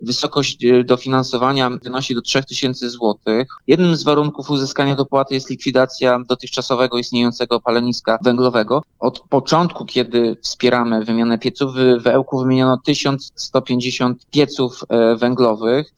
Mówił prezydent Ełku Tomasz Andrukiewicz.